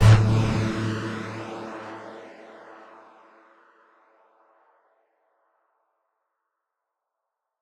Bass_A_02.wav